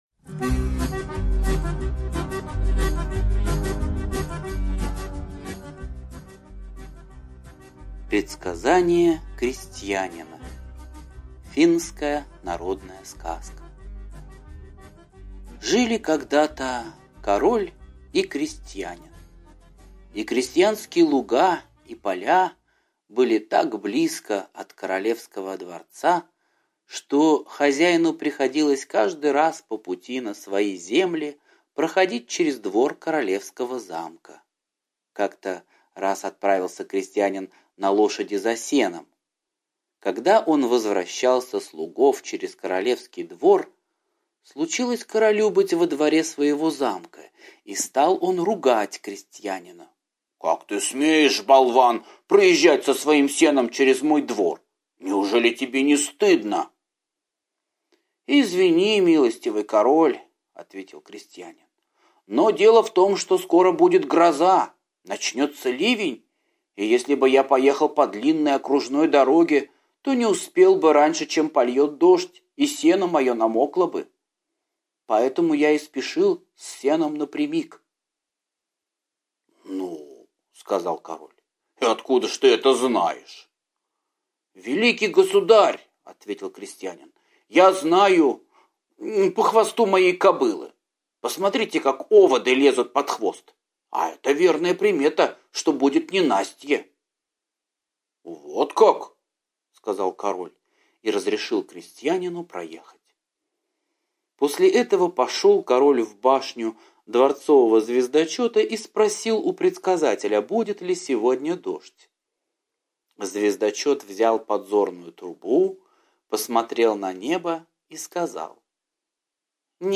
Предсказание крестьянина - финская аудиосказка - слушать онлайн